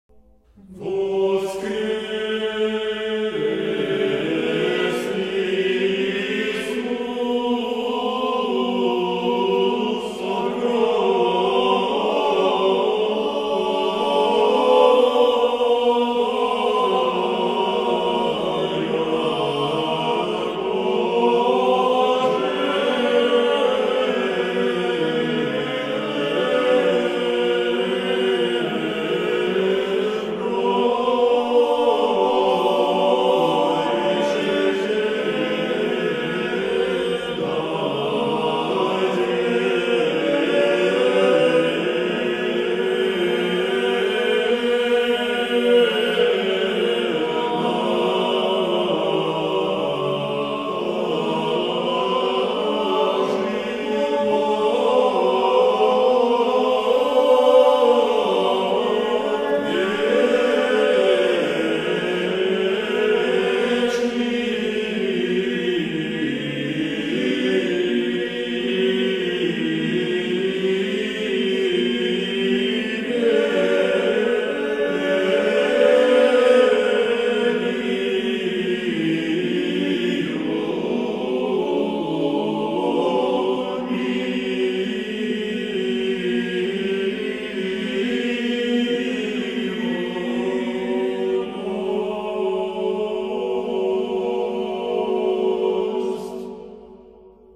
Начинается оно всегда с унисона трех голосов мужского хора, а завершается аккордом из трех звуков.
Основной голос троестрочия Путь обвивается сверху и снизу двумя его вариантами – Верхом и Низом, расходящимися из начального унисона.
Воскрес Иисус от гроба. Троестрочие